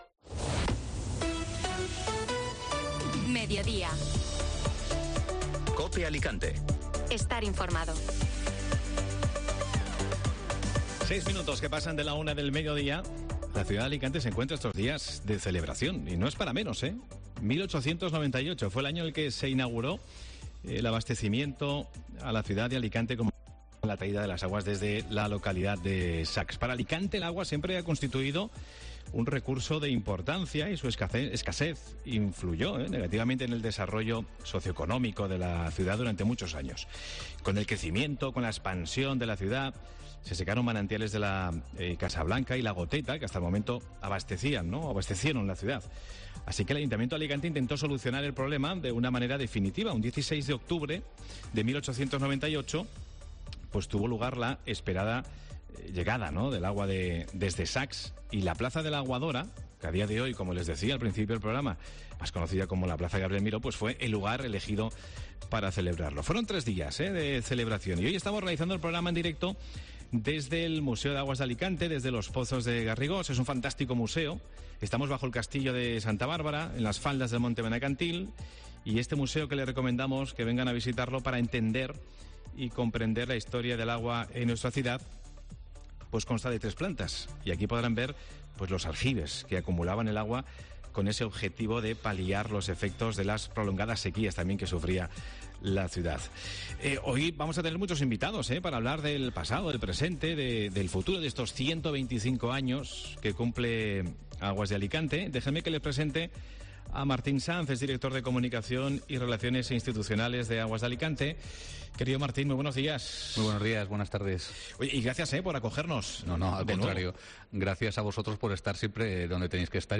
AUDIO: Mediodía COPE Alicante realiza el programa en directo desde los pozos de Garrigós con motivo del 125 aniversario de la llegada del agua a la...